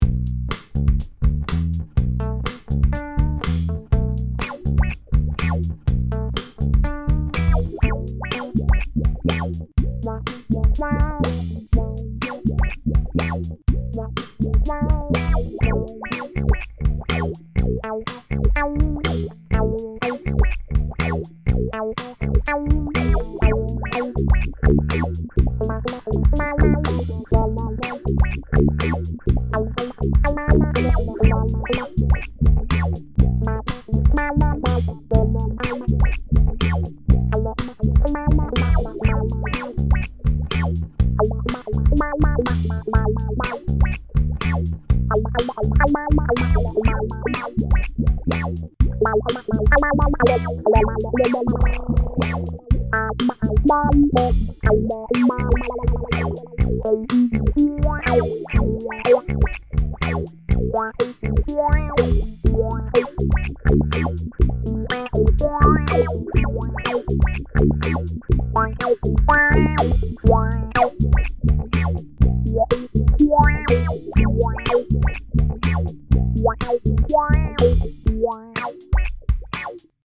Stereo Envelope Filter, Wah-Wah, and Sample Hold Filter
Either filter can be run as an ENVELOPE FILTER, WAH-WAH pedal, or a RANDOM OSCILLATING FILTER much like a SAMPLE AND HOLD FILTER.
Multi-Tron Demo Clip